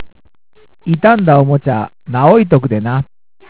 方言